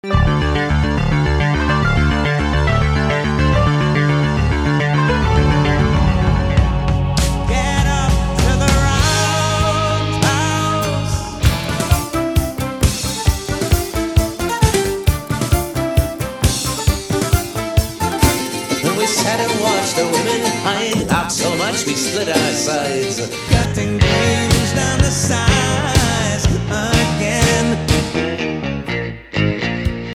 Bring me my sampler mix as a talisman: